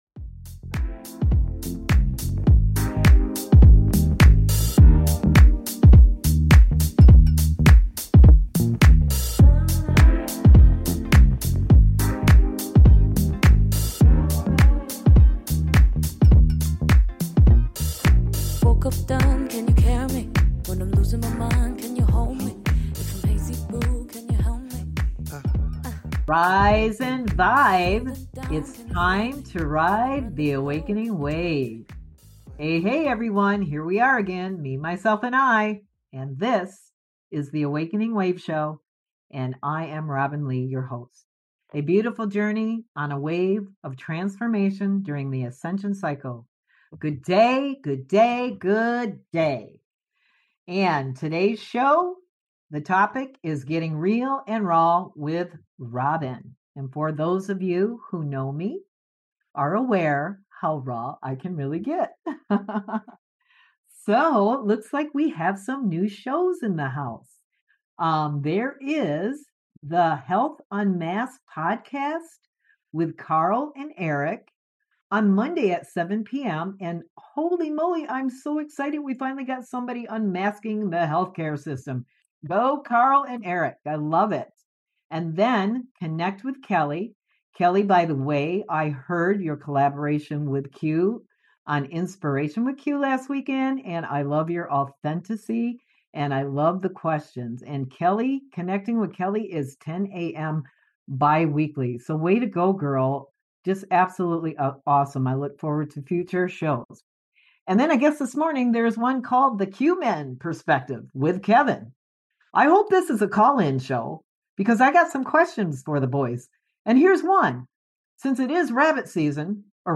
Talk Show
Each episode with feature in-depth discussions, listener interactions, and guest appearances.